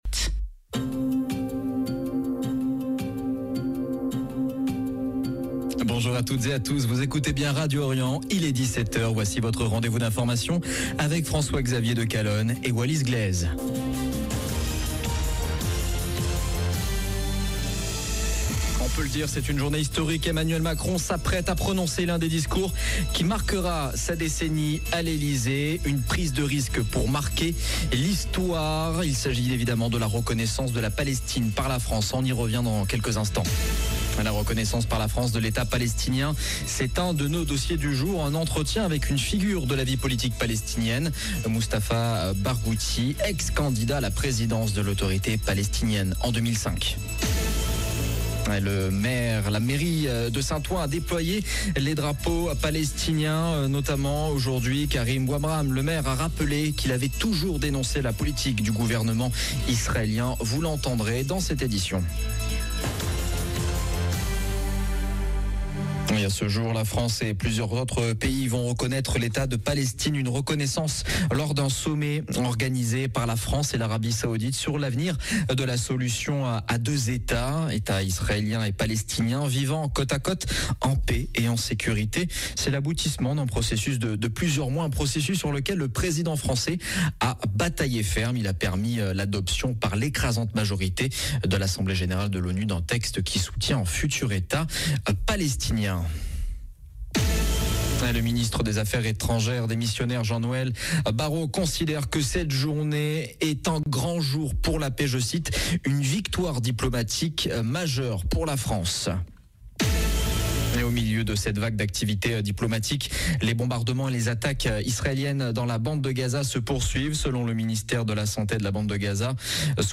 La reconnaissance par la France de l’État palestinien, c’est l’un de nos dossiers. Un entretien avec une figure de la vie politique palestinienne, Mustafa Barghouthi, ancien candidat à la présidence de l’Autorité palestinienne en 2005.